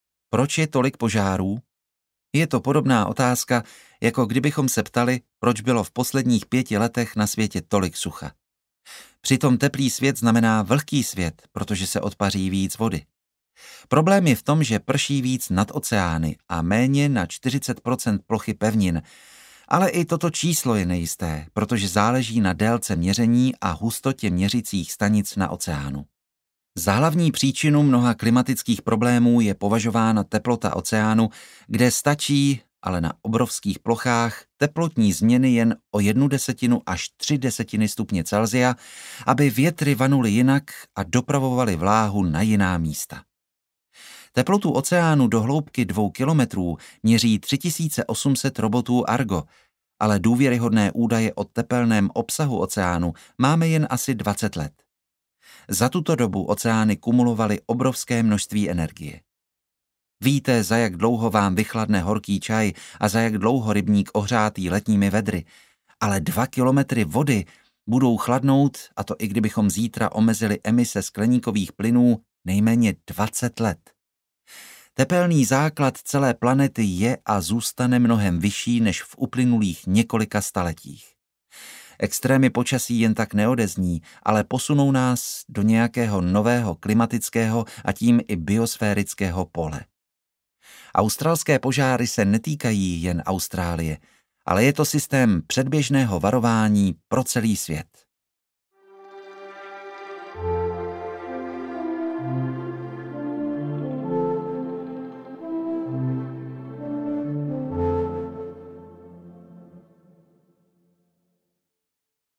Jak přejít řeku audiokniha
Ukázka z knihy